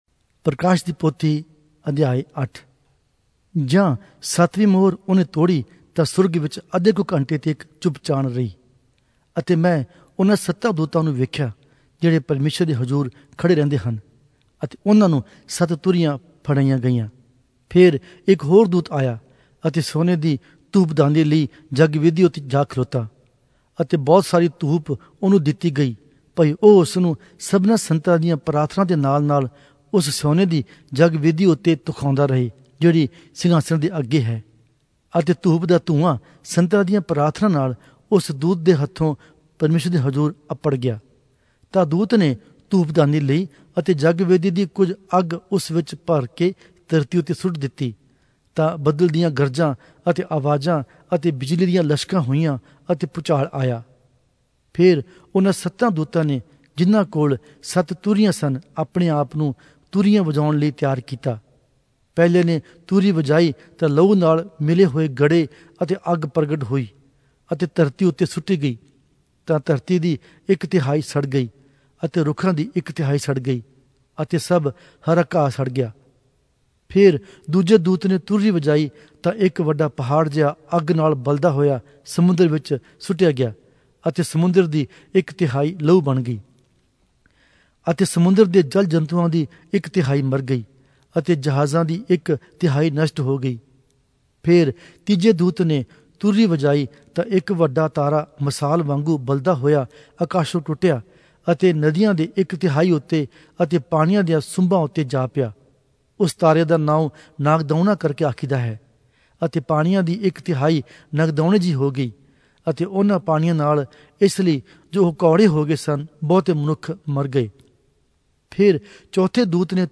Oriya Audio Bible - Revelation 1 in Erven bible version